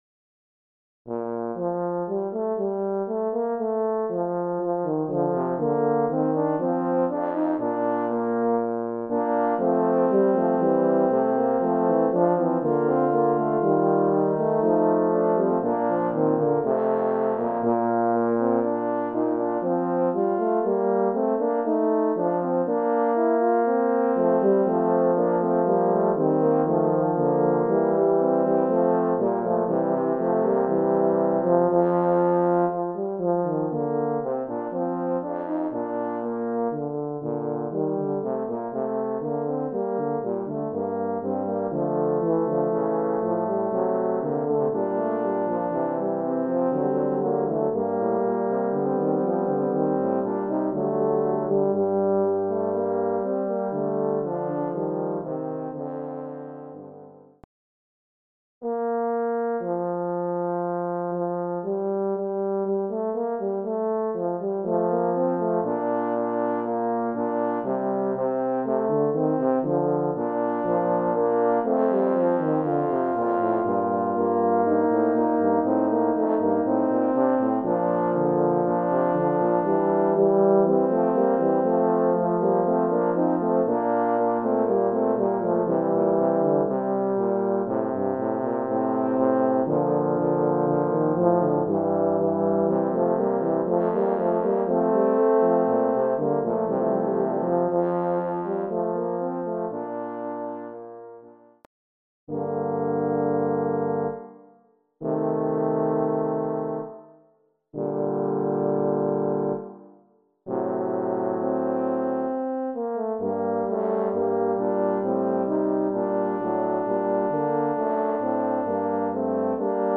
Voicing: Horn Trio